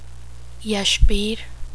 English: Yashbir, Yash + Bir or Yash + Vir
Pronunciation: